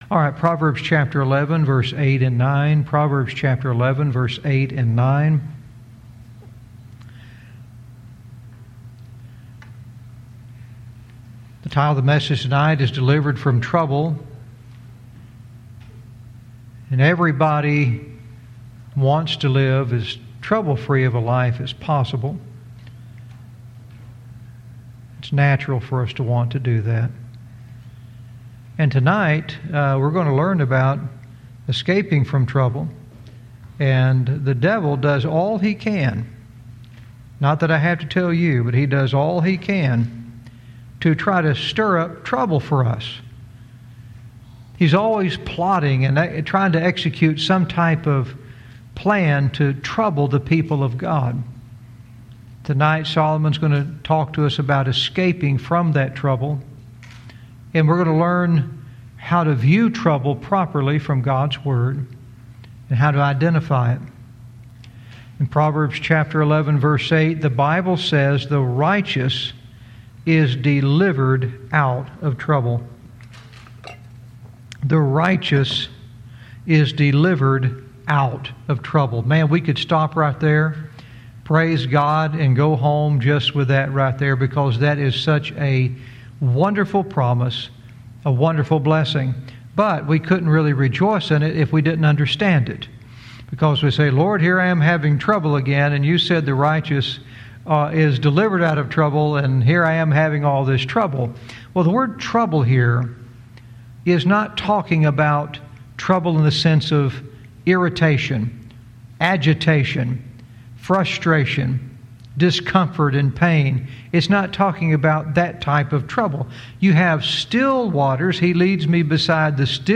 Verse by verse teaching - Proverbs 11:8-9 "Delivered From Trouble"